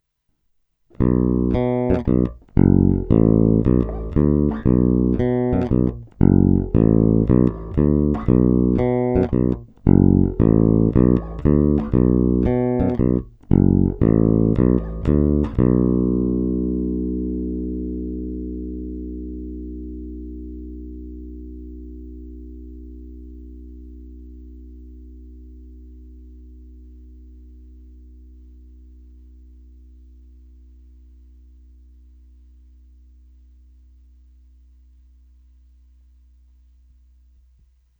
Zvuk je naprosto klasický Jazz Bass, zvláště v pasívním režimu.
Zvuk je obecně poměrně ostrý, agresívní, naštěstí není problém korekcemi výšek umravnit, nebo to pak lze samozřejmě řešit na aparátu.
Není-li uvedeno jinak, následující nahrávky jsou provedeny rovnou do zvukové karty, jen normalizovány, jinak ponechány bez úprav.
Snímač u kobylky